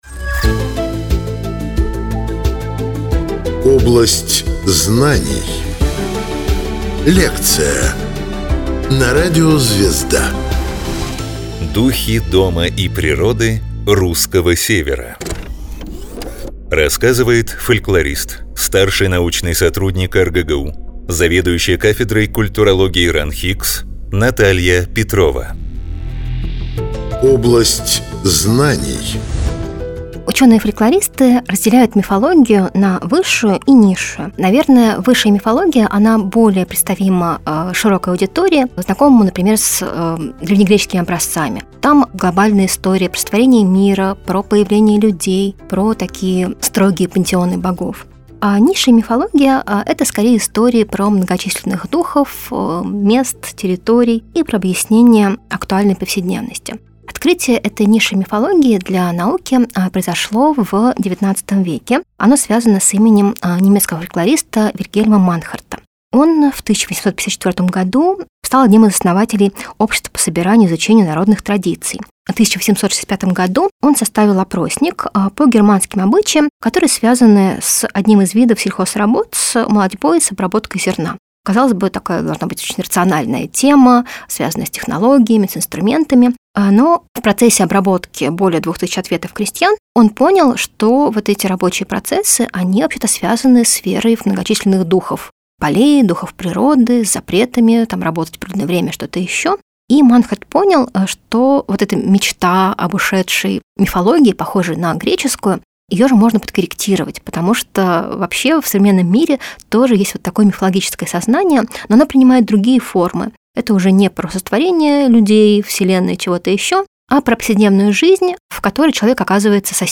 Авторские лекции о космосе, истории, литературе и многом другом.